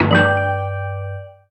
throwoffstun.ogg